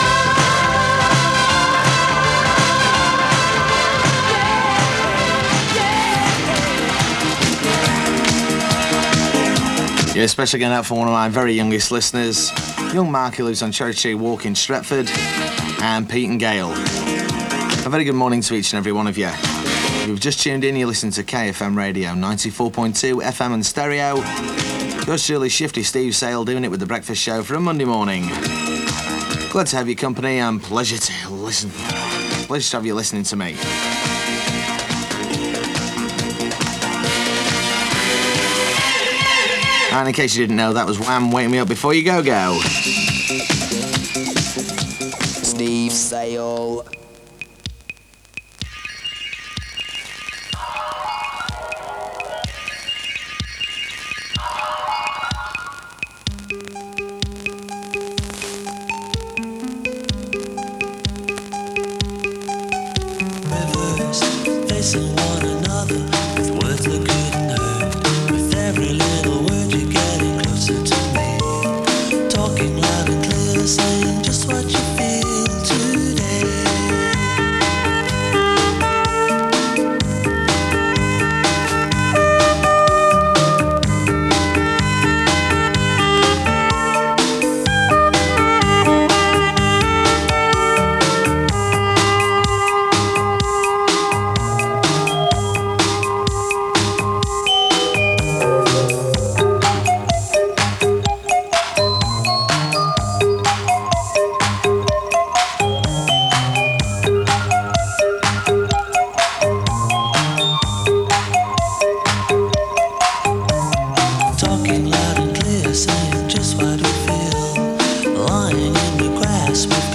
As received near Blackpool from 94.2MHz in mono.